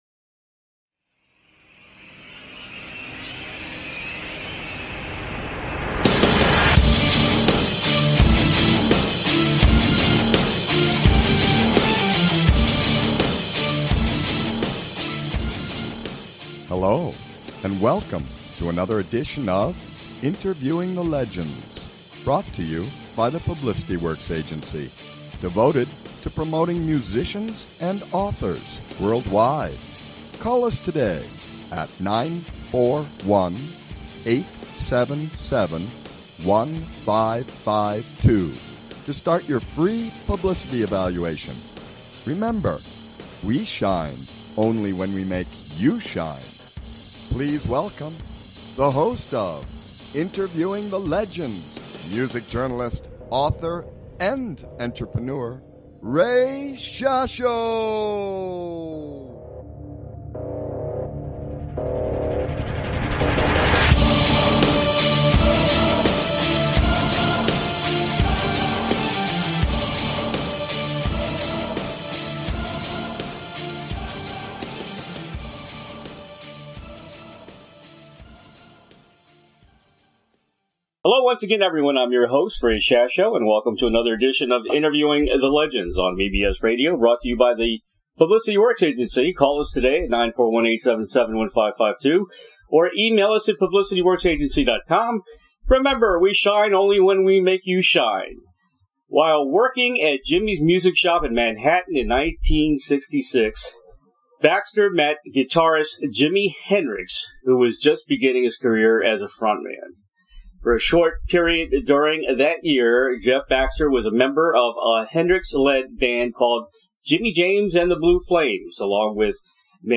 Jeff Skunk Baxter Legendary Guitarist w/Steely Dan & The Doobie Brothers Special Guest on Interviewing the Legends